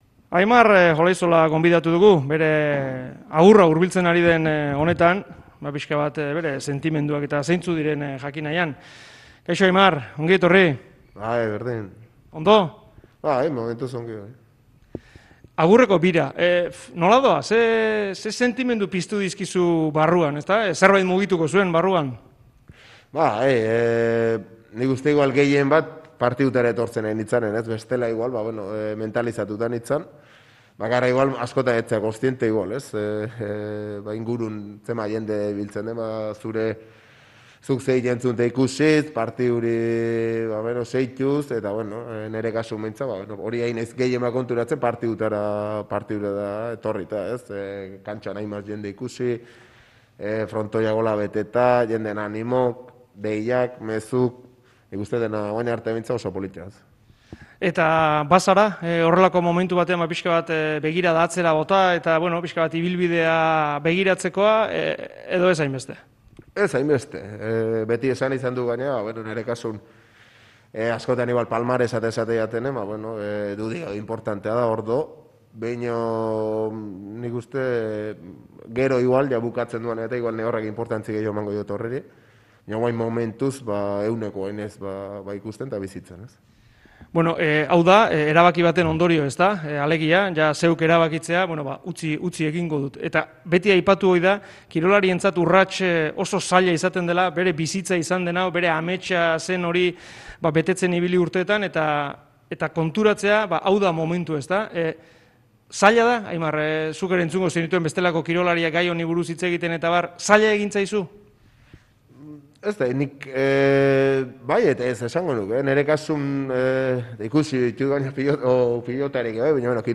Pelotari goizuetarrak datorren larunbatean jokatuko du azken partida jaioterrian. Aurrez, Euskadi Irratiko mikrofonoetan bere ibilbidearen errepasoa egin du.